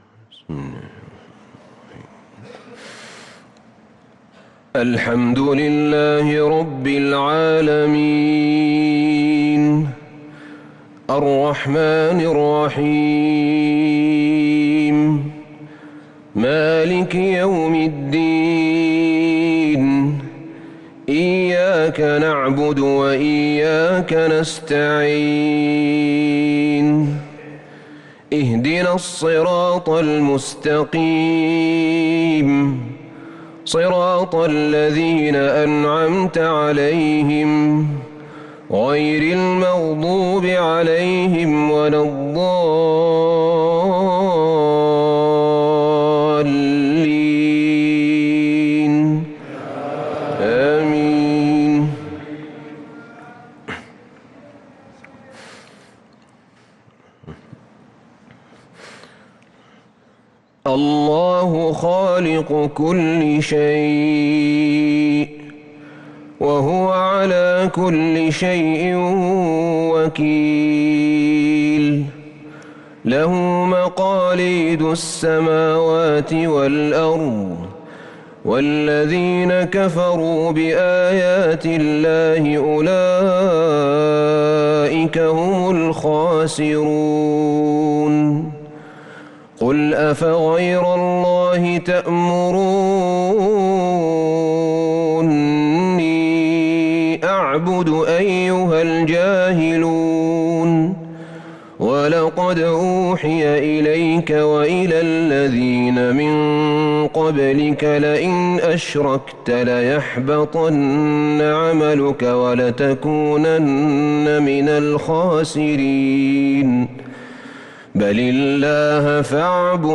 صلاة العشاء للقارئ أحمد بن طالب حميد 30 رمضان 1443 هـ
تِلَاوَات الْحَرَمَيْن .